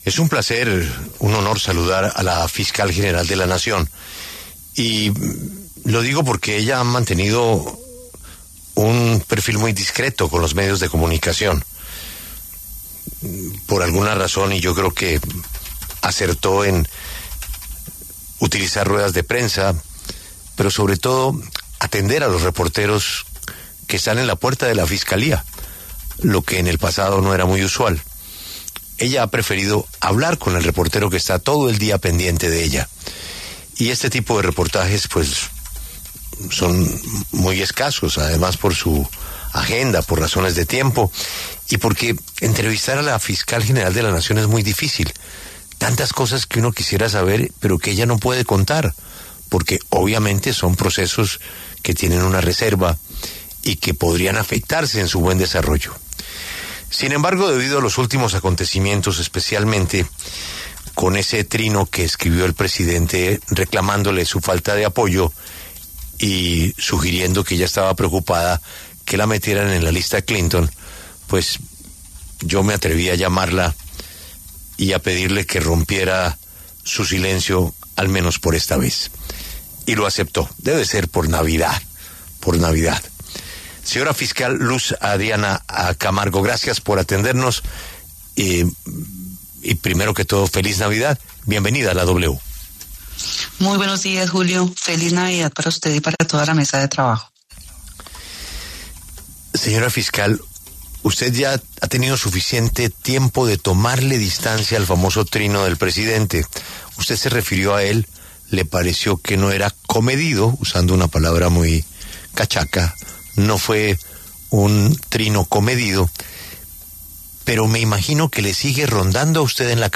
Luz Adriana Camargo, fiscal general de la Nación, habla en La W